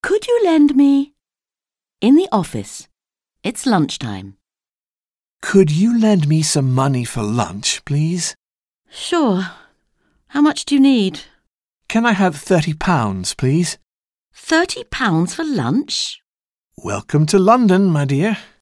Dialogue - Could you lend me…?